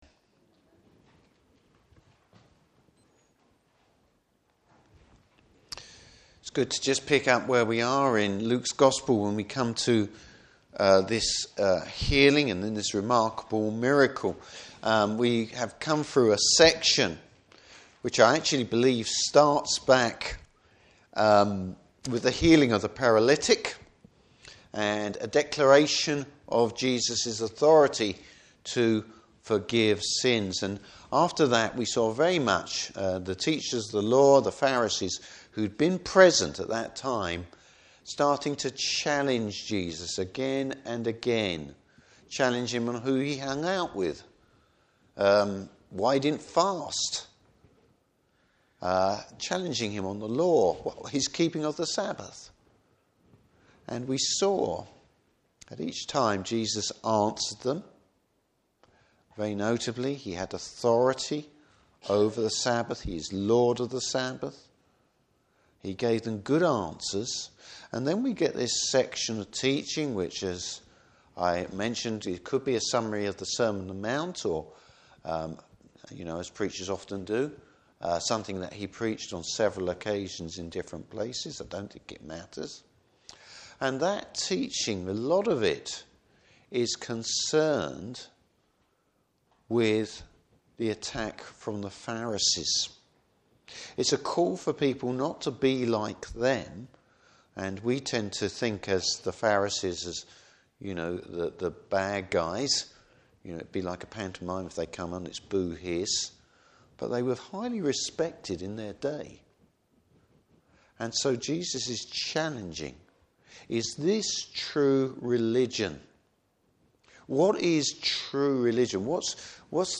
Service Type: Morning Service Bible Text: Luke 7:1-17.